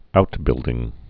(outbĭldĭng)